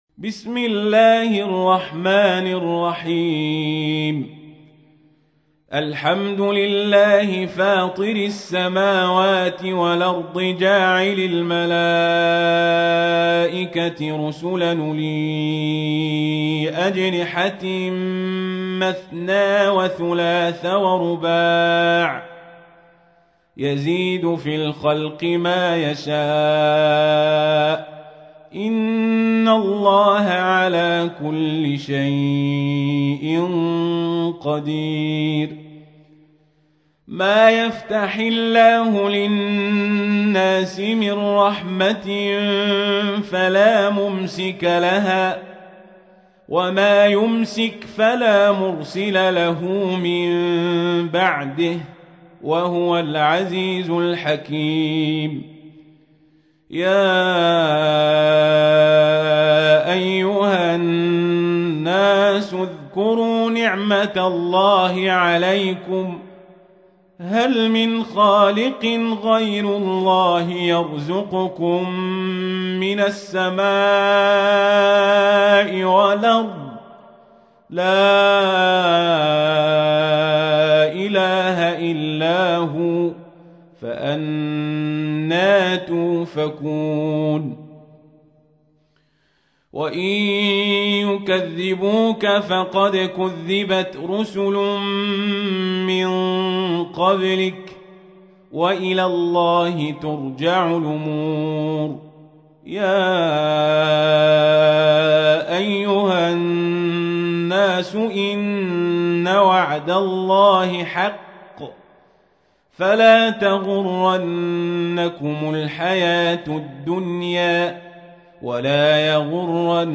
تحميل : 35. سورة فاطر / القارئ القزابري / القرآن الكريم / موقع يا حسين